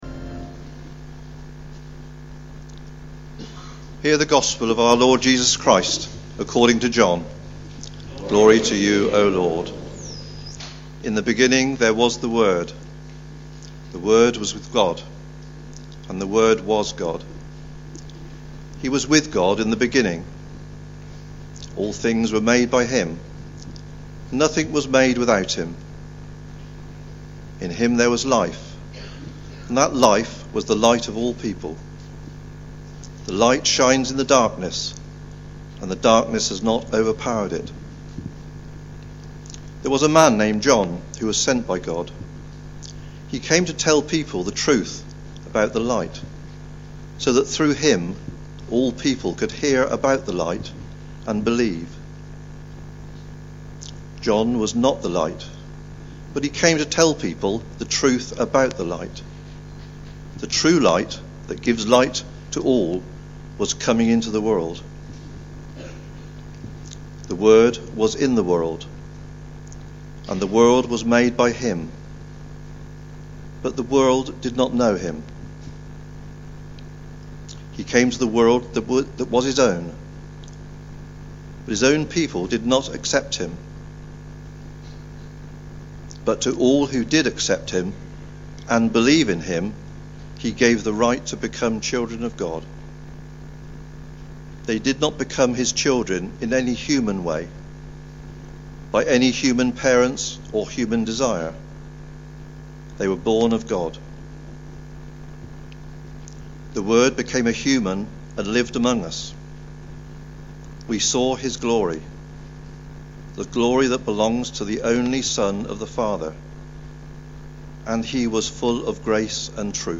Christmas Eve 2011 – Midnight Mass